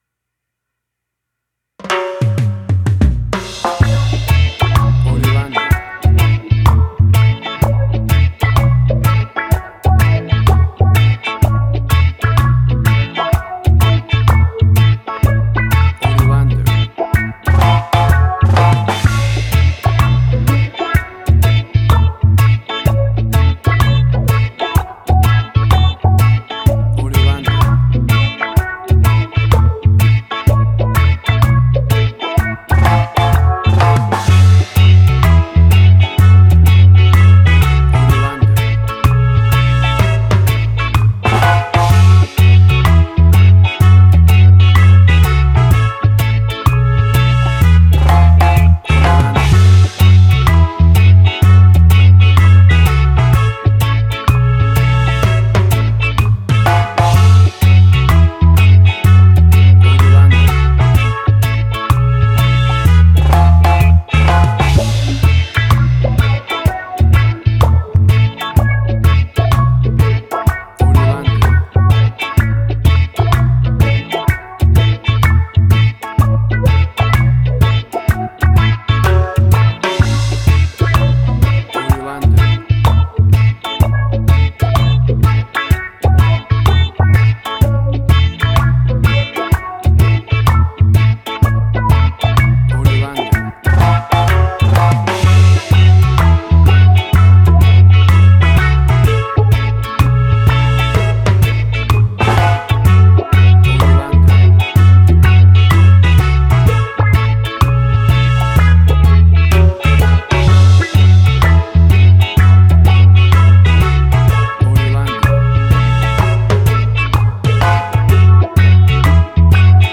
Classic reggae music with that skank bounce reggae feeling.
WAV Sample Rate: 16-Bit stereo, 44.1 kHz
Tempo (BPM): 63